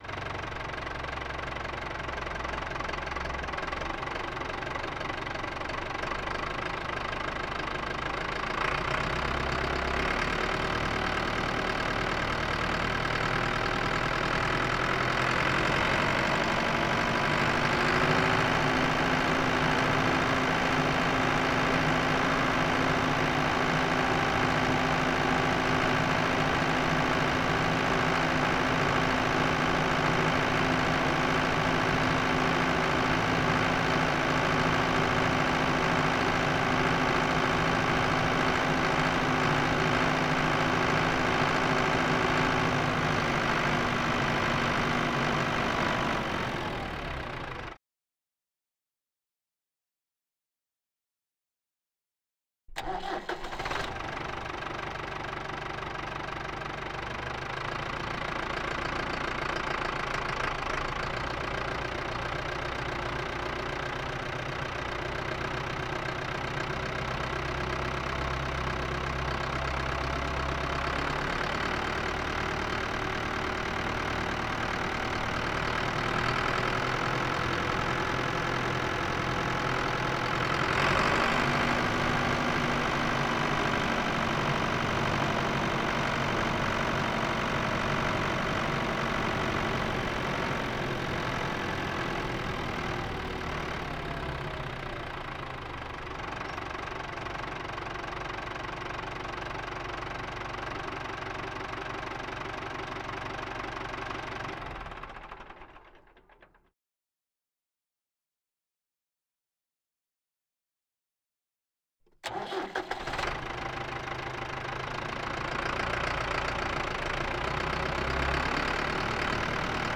Ford Tractor - t9 - ONBRD - MEDIUM - Drive Various - ENGINE - Right - MKH8020.wav